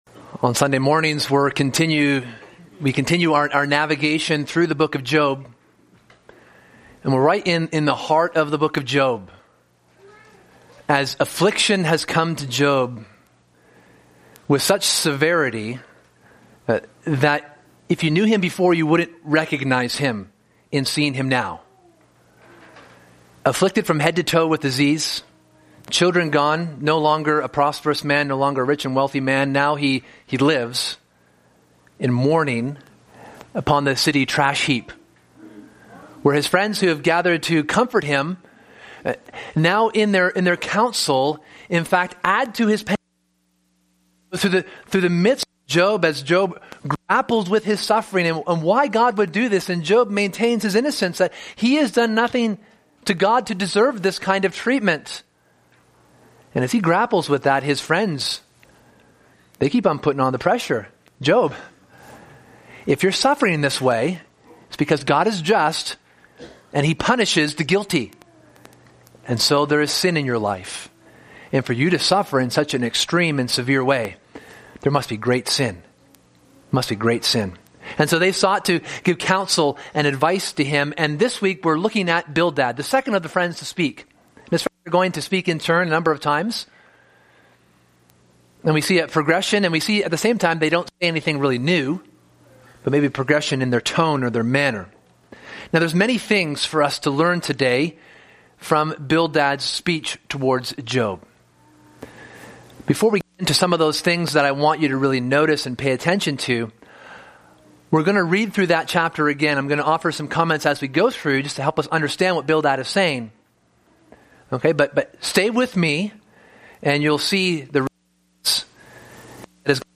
Sermon: What It Means to Know God